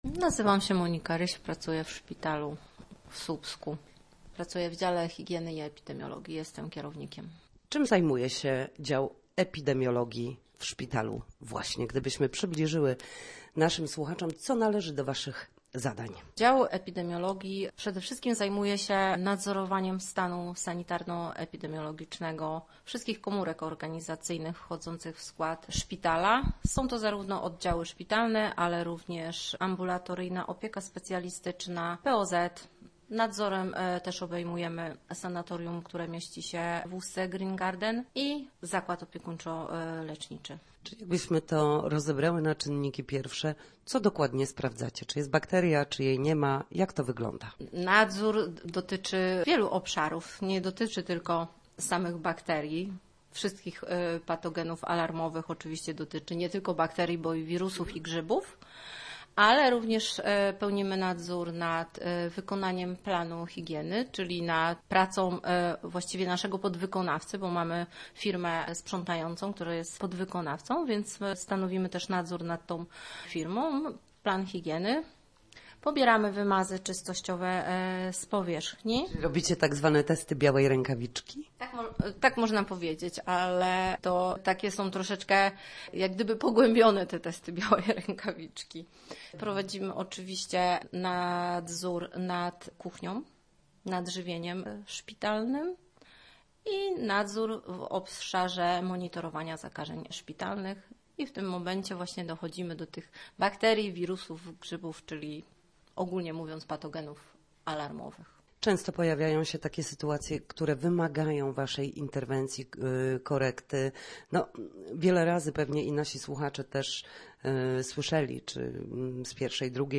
Na antenie wyjaśniała, co należy do obowiązków jego pracowników.